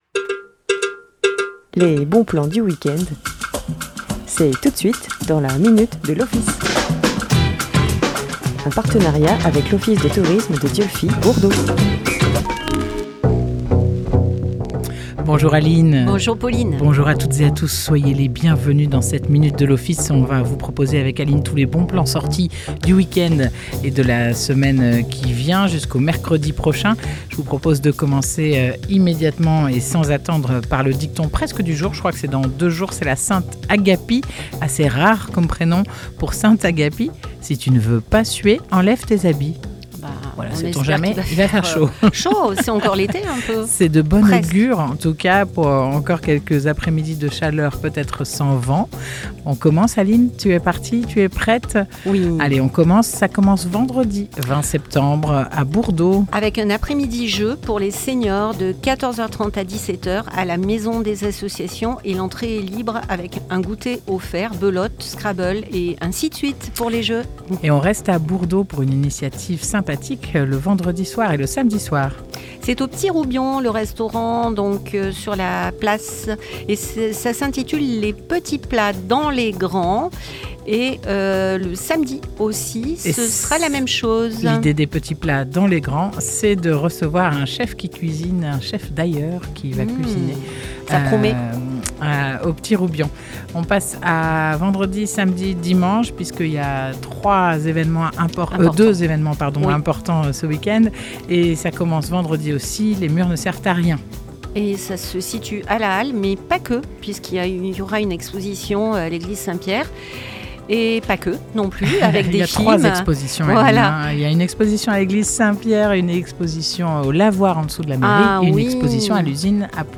Chaque mercredi à 9h30 en direct